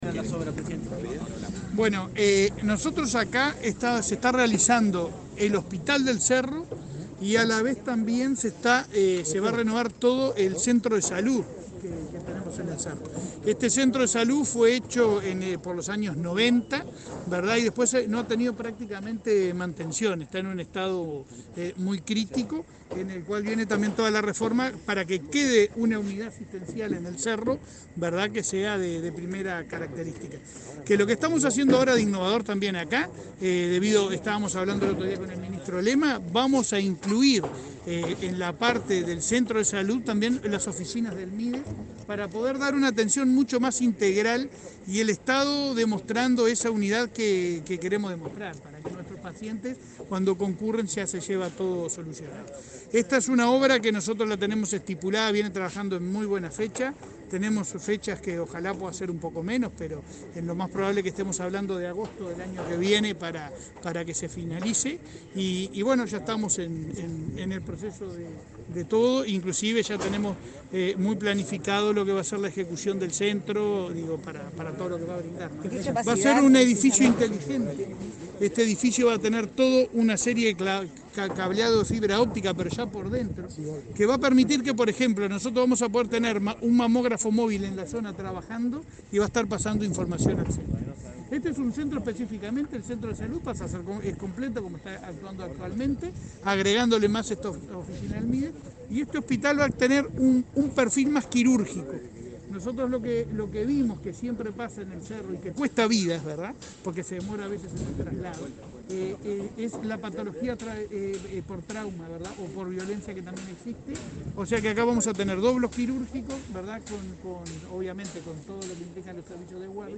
Declaraciones a la prensa del presidente de ASSE, Leonardo Cipriani
Luego, Cipriani dialogó con la prensa.